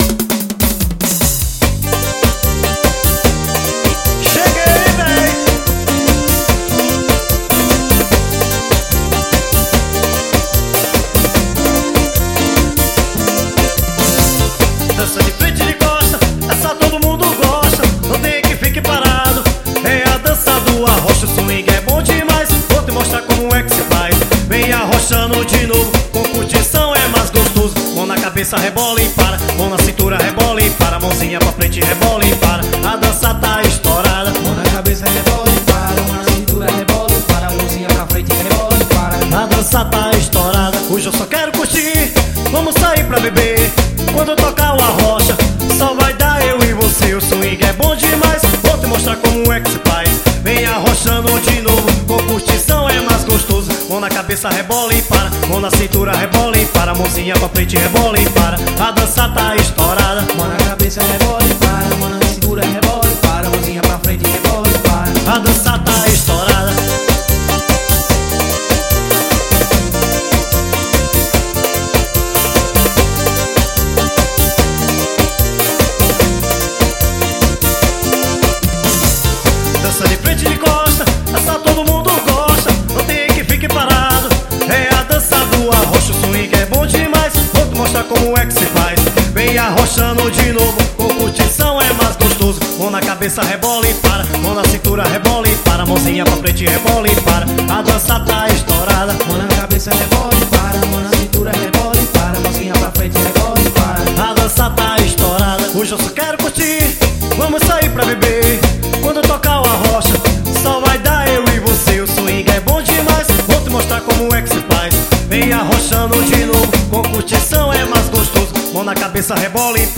forro.